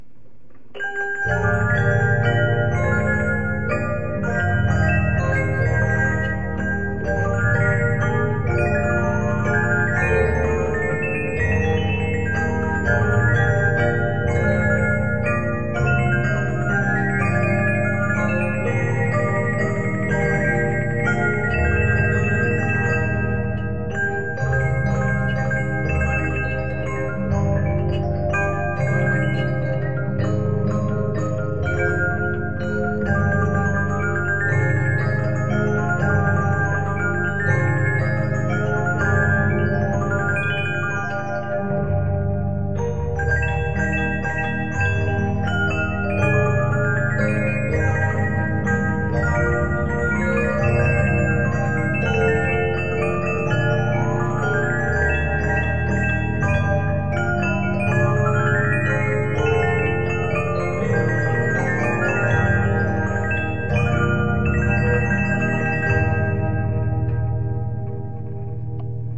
mira music box
１２インチの場合でも、レジーナ１５．５インチクラスに近い演奏は可能ですが１８．５インチは圧倒的に高中音の厚みが違い重低音がさらに加わります。
１８．５インチダブルコーム（コンソール）ランゲの「花の歌」
（音の厚みの中には細かい装飾音が隠れていますので、できればヘッドホンなどで聞いてください。）
18.5 太い低音の歯による重低音が加わる 約１分
ミラの美しい音色はやはりダブルコームの軽い透明感のある高音と、体にずっしりと伝わる重低音です。